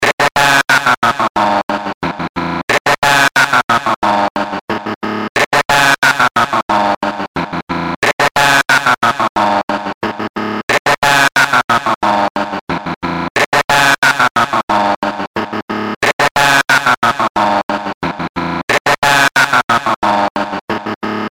Down Fall Distorted.wav